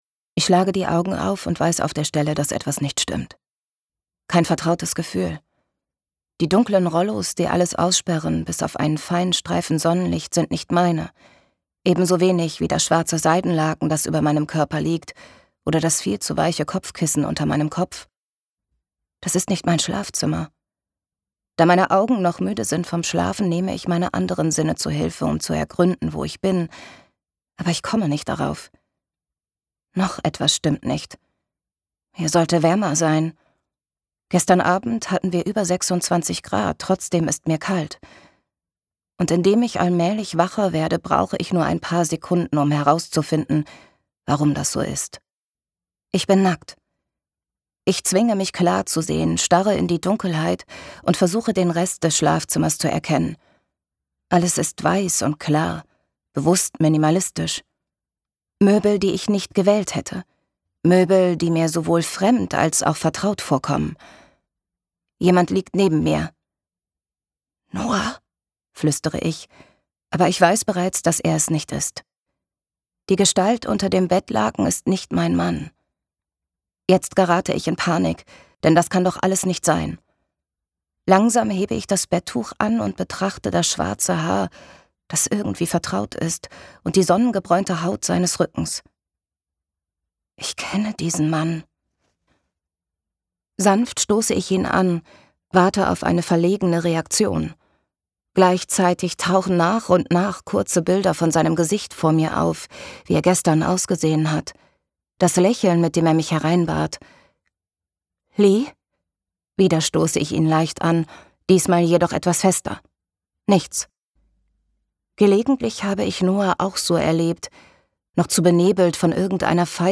Interpretin: